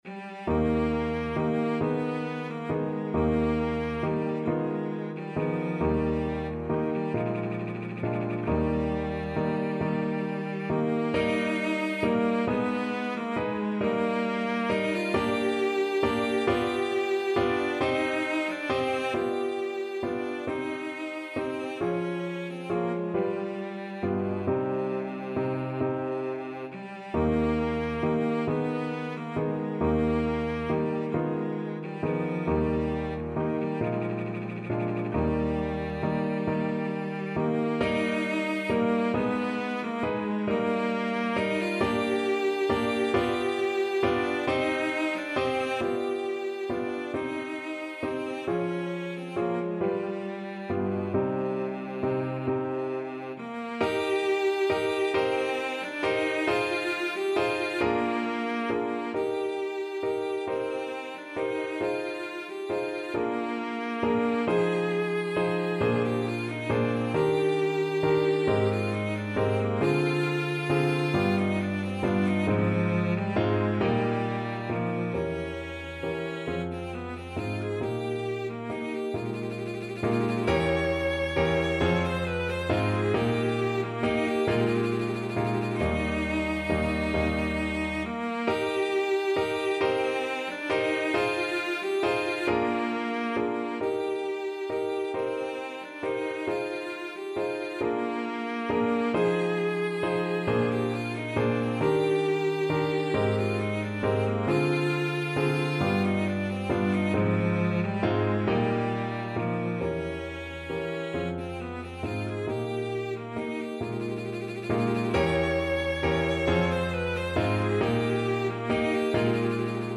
6/8 (View more 6/8 Music)
Andantino .=c.45 (View more music marked Andantino)
Classical (View more Classical Cello Music)